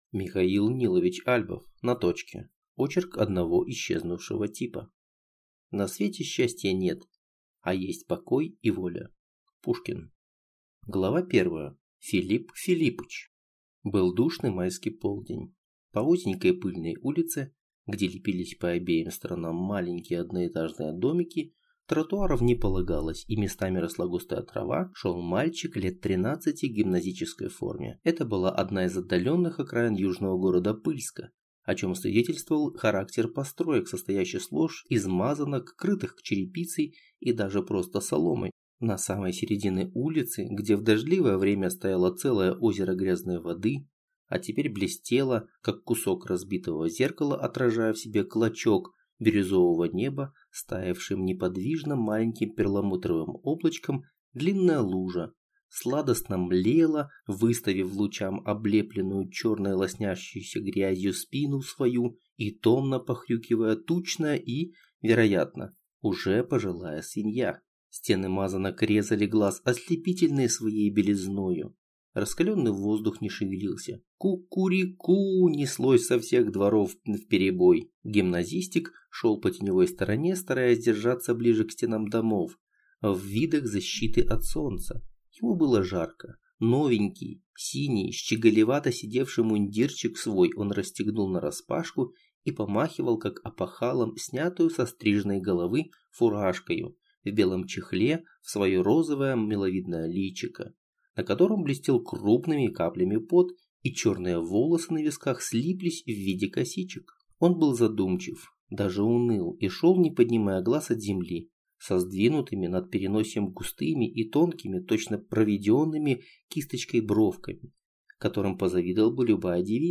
Аудиокнига На точке | Библиотека аудиокниг